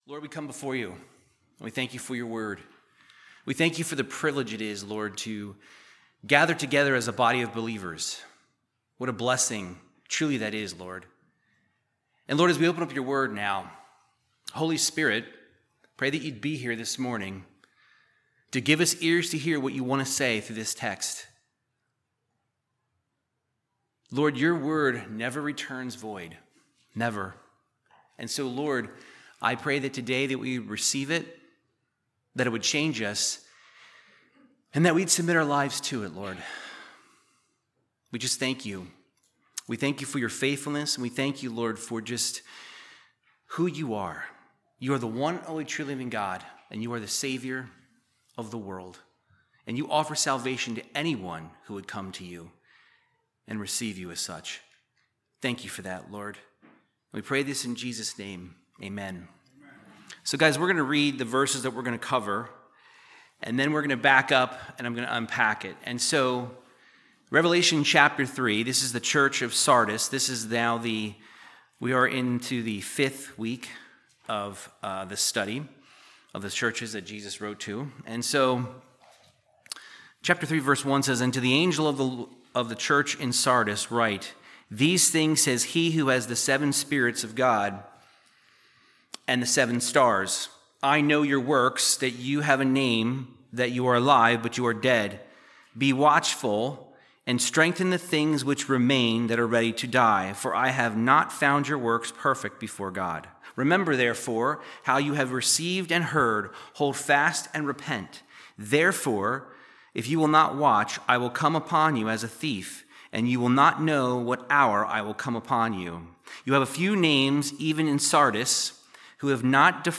Verse by verse Bible teaching of the book of Revelation chapter 3 verses 1-6